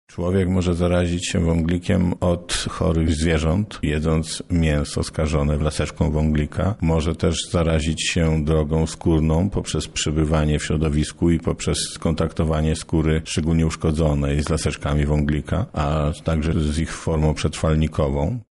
Mimo, że choroba jest niebezpieczna nie mamy się czego obawiać – mówi Lubelski Państwowy Wojewódzki Inspektor Sanitarny, Mirosław Starzyński.